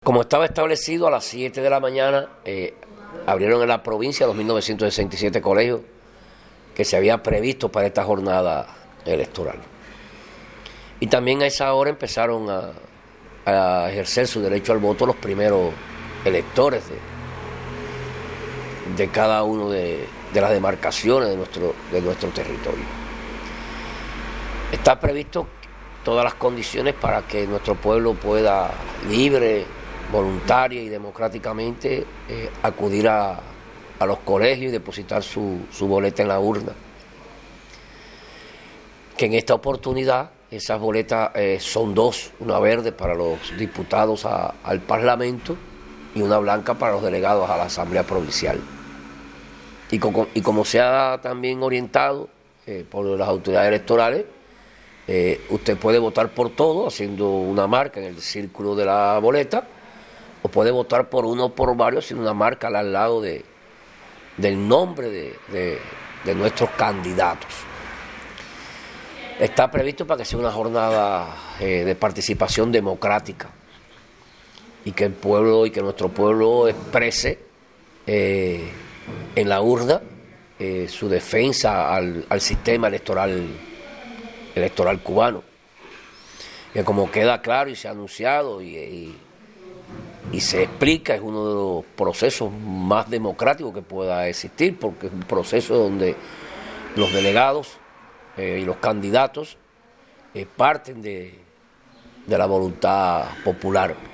Declaraciones de Antonio de Marcos Ramírez, presidente de la Comisión Electoral Provincial